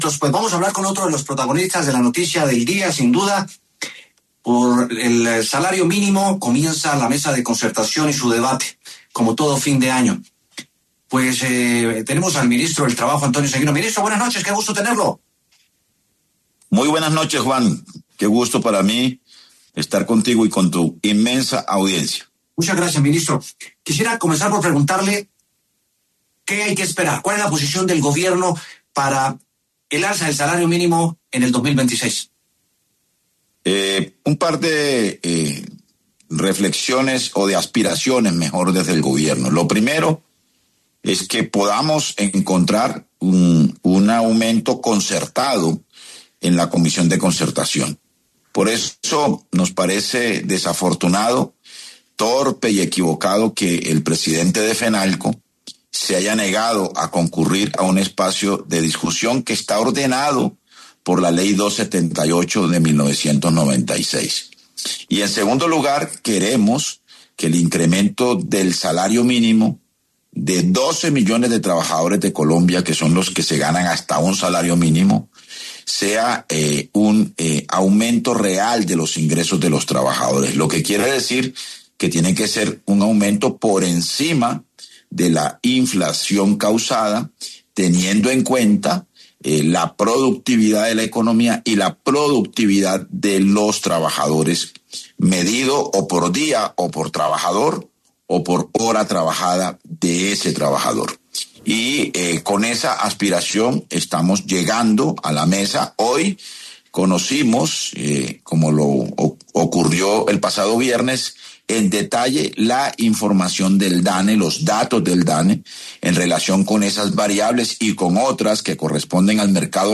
El ministro del Trabajo, Antonio Sanguino, conversó con W Sin Carreta a propósito de la mesa de concertación que se instaló para que empresarios, trabajadores y Gobierno lleguen a un acuerdo para el incremento del salario mínimo del 2026.